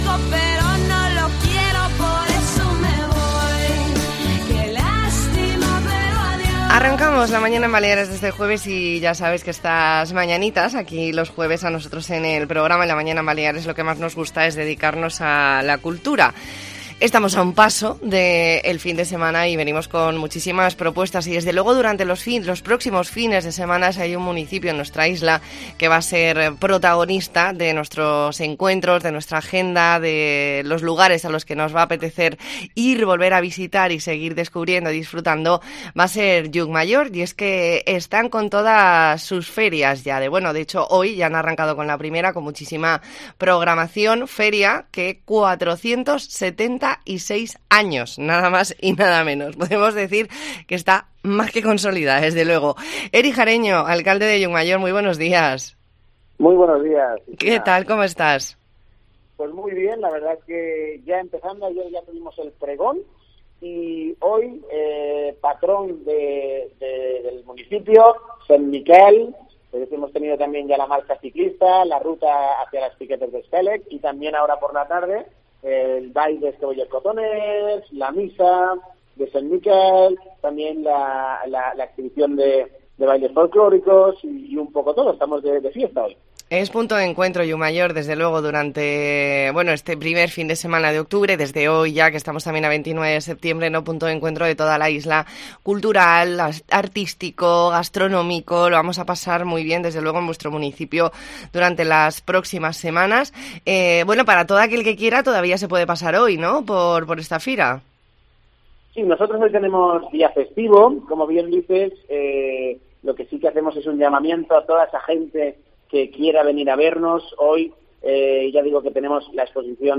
Redacción digital Madrid - Publicado el 29 sep 2022, 12:30 - Actualizado 18 mar 2023, 21:35 1 min lectura Descargar Facebook Twitter Whatsapp Telegram Enviar por email Copiar enlace Hablamos con Eric Jareño, alcalde de Llucmajor .